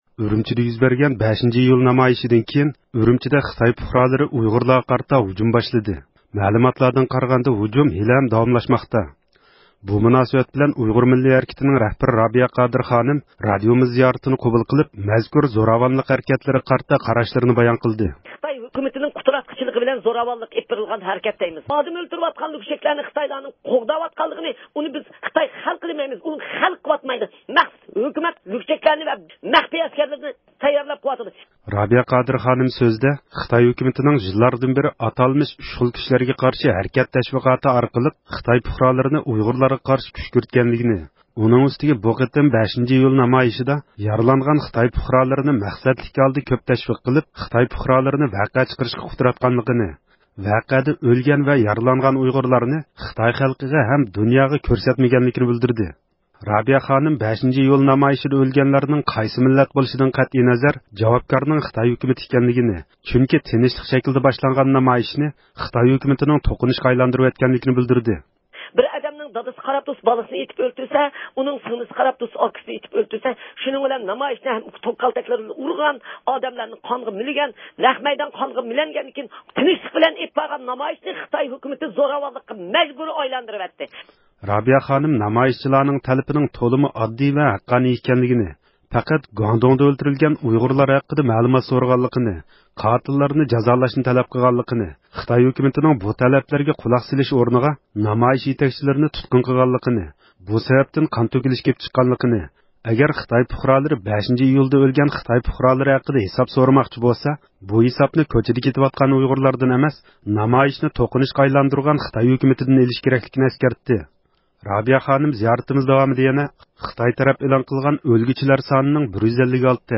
بۇ مۇناسىۋەت بىلەن ئۇيغۇر مىللىي ھەرىكىتىنىڭ رەھبىرى رابىيە قادىر خانىم زىيارىتىمىزنى قوبۇل قىلىپ، مەزكۇر زوراۋانلىق ھەرىكەتلىرىگە قارىتا قاراشلىرىنى بايان قىلدى.